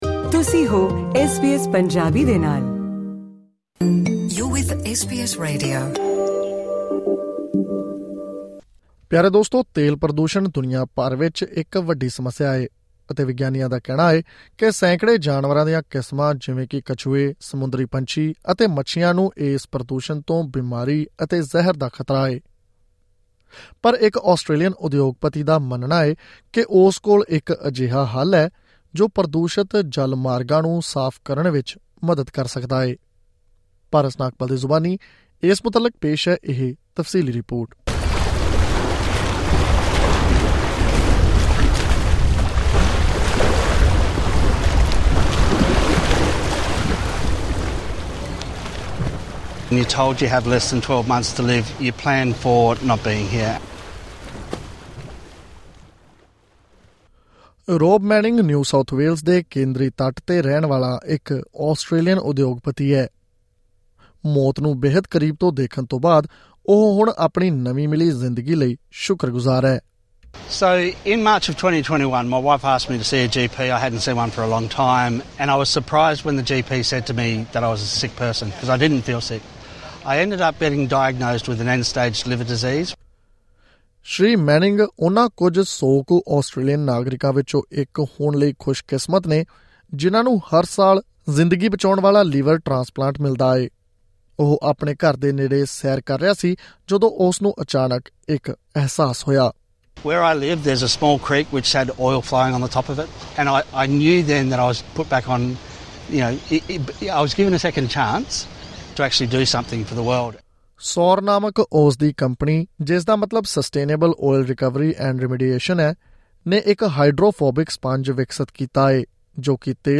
ਐਸ ਬੀ ਐਸ ਪੰਜਾਬੀ ਤੋਂ ਆਸਟ੍ਰੇਲੀਆ ਦੀਆਂ ਮੁੱਖ ਖ਼ਬਰਾਂ: 28 ਅਕਤੂਬਰ 2024